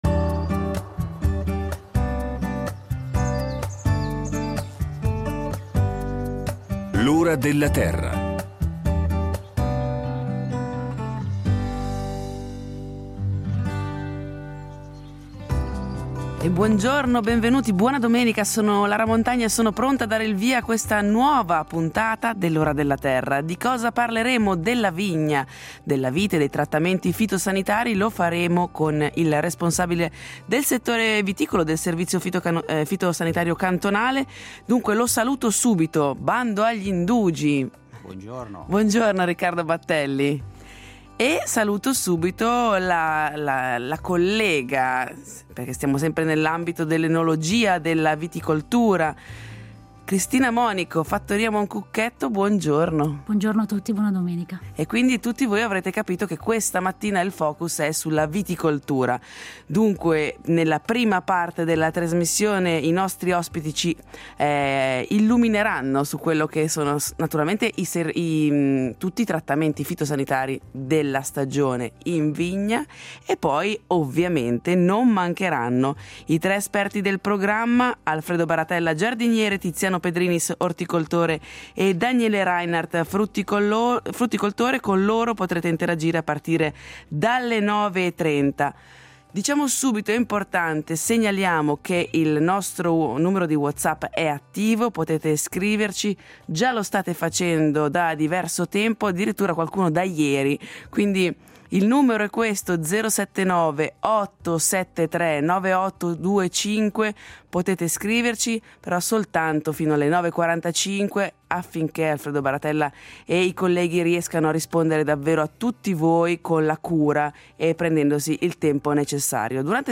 Cercheremo di capire quali trattamenti sono obbligatori, come trattare e con quale prodotto. Ci saranno anche gli esperti del programma per rispondere alle domande del pubblico.